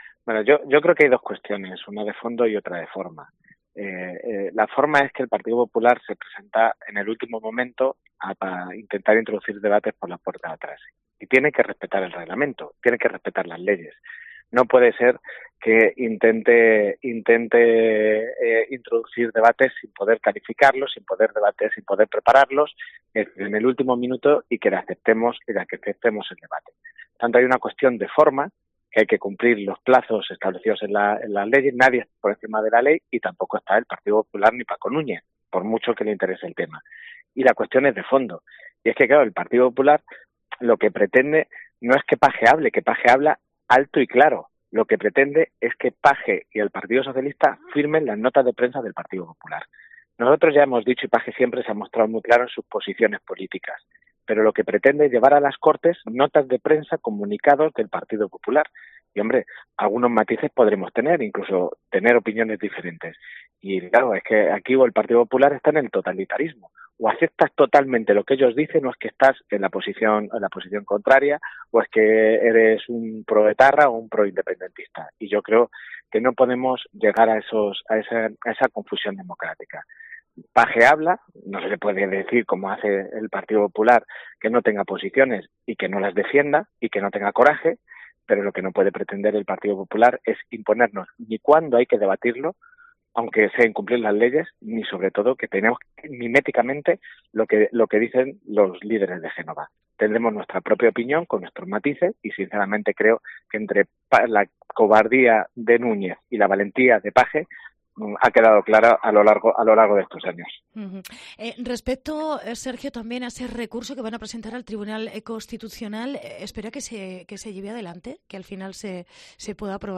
Sergio Gutiérrez secretario de organización de PSOE regional en COPE CLM